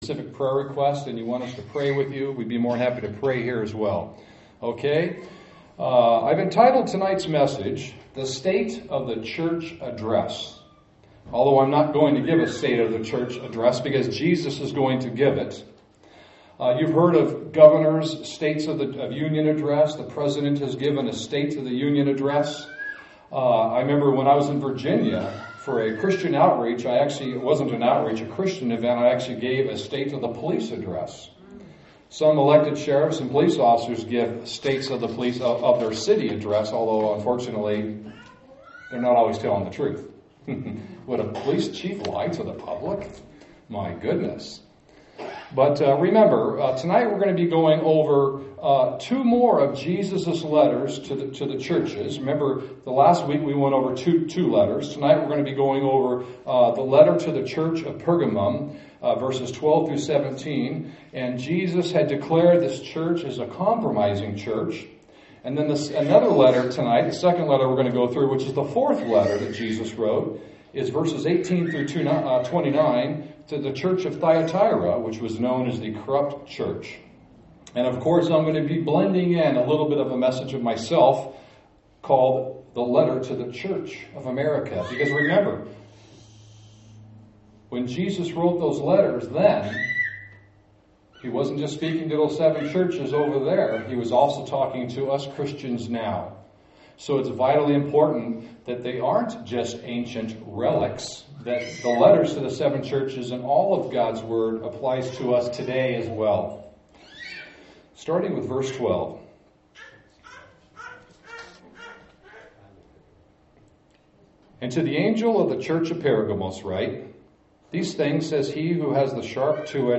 Update: Here is the audio from my above message. Jesus’ letter to the church of Pergamum & how it applies to America.